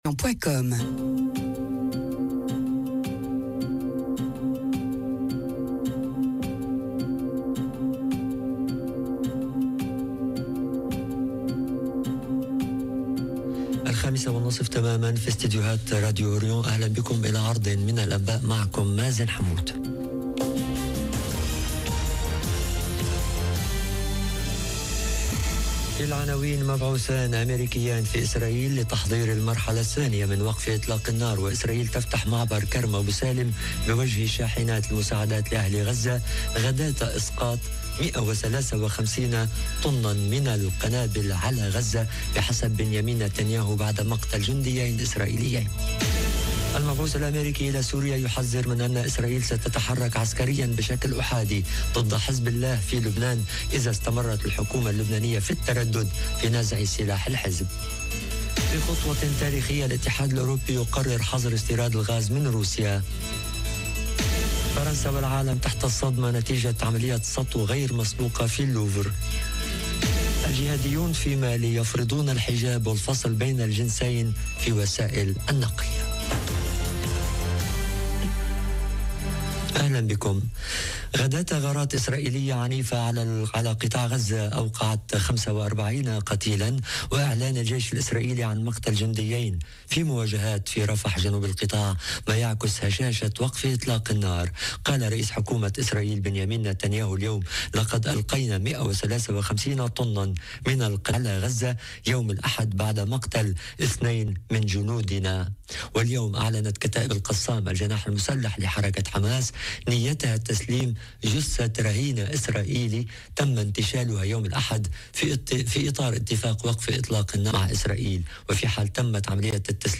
نشرة أخبار المساء: مبعوثان اميركيان في إسرائيل لتحضير المرحلة الثانية من وقف إطلاق النار وإسرائيل تفتح معبر كرم أبو سالم بوجه شاحنات المساعدات لأهل غزة - Radio ORIENT، إذاعة الشرق من باريس